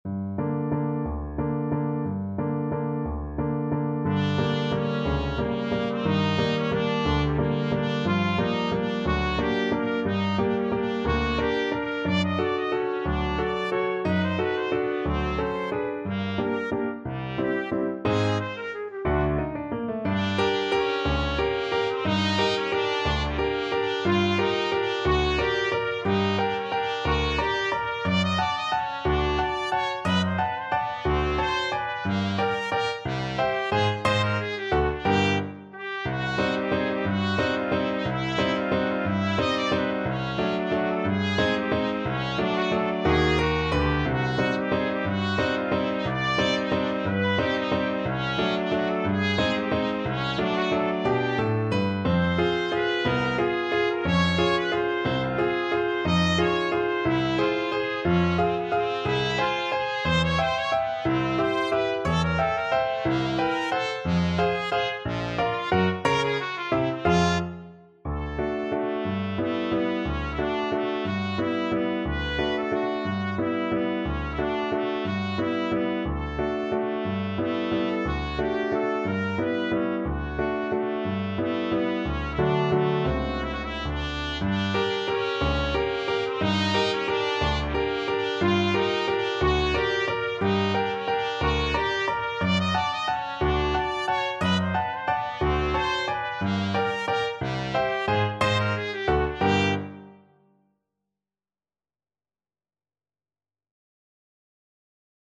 3/4 (View more 3/4 Music)
Allegro espressivo .=60 (View more music marked Allegro)
F4-Eb6
Classical (View more Classical Trumpet Music)